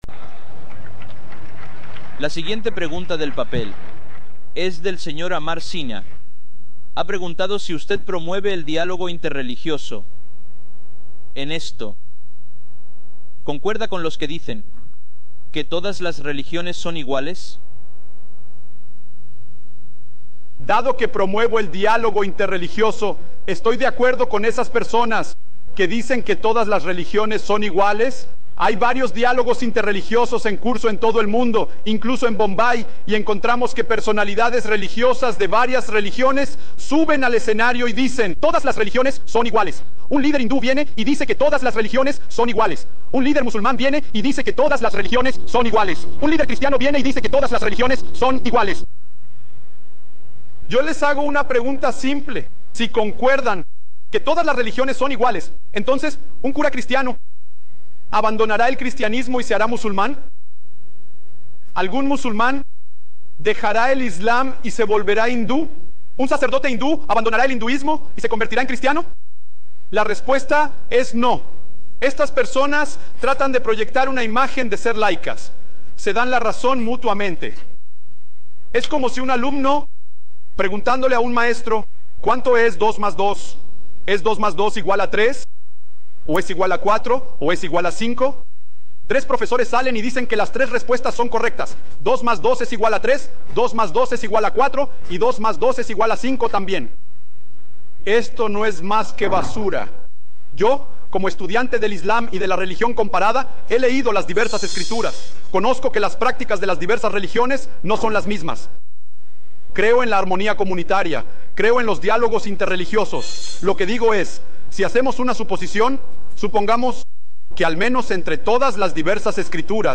Descripción: En este video, el Dr. Zakir Naik explica por qué no es justo afirmar que todas las religiones son iguales.